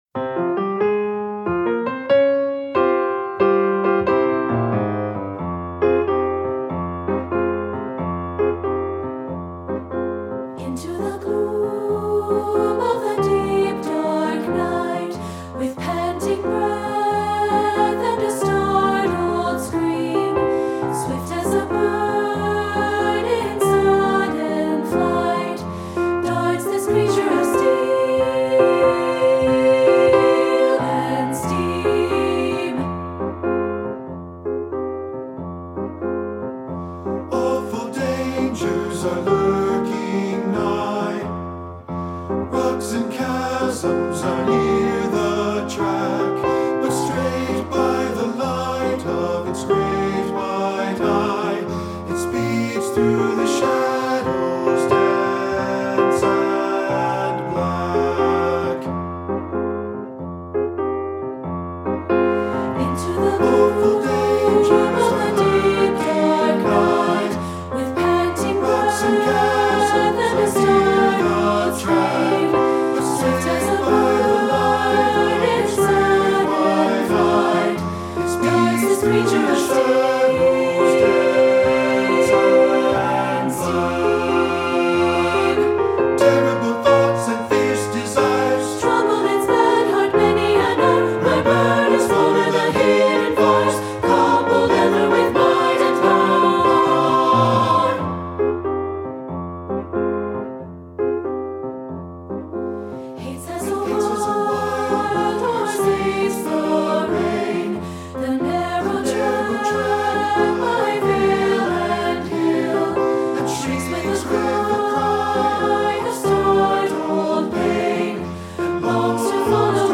Choral Concert/General
3 Part Mix
3-Part Mixed Audio